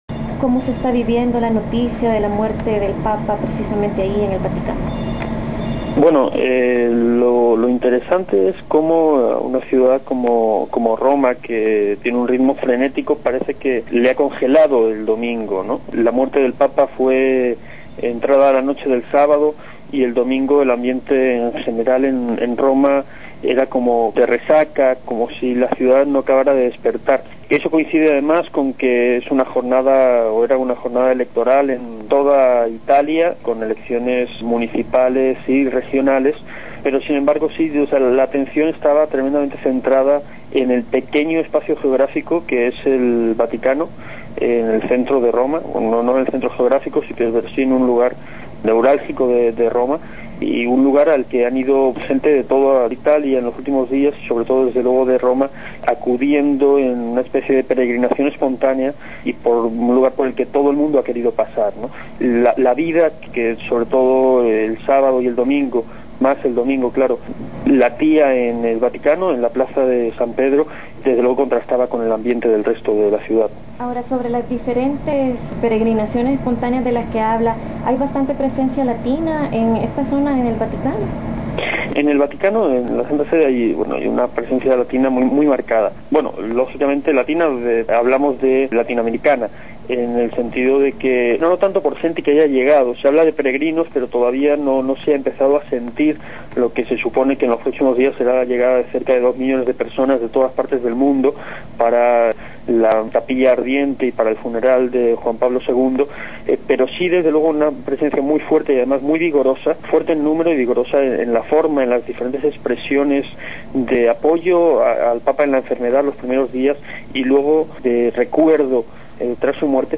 Reporte desde el Vaticano